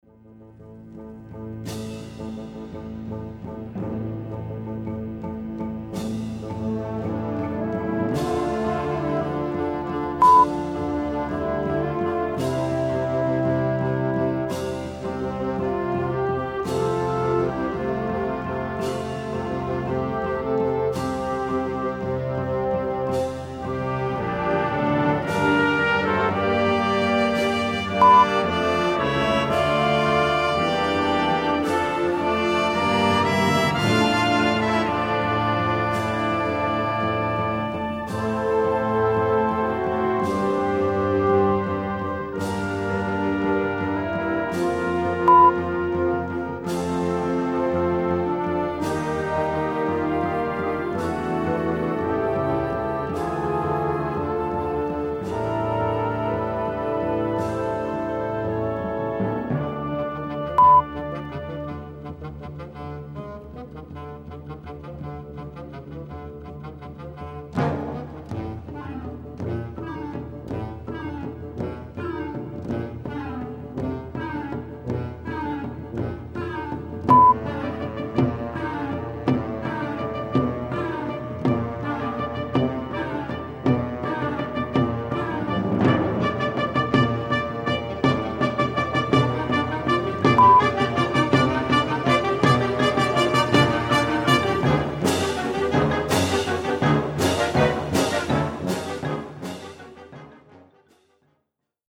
Besetzung: Sinfonieorchester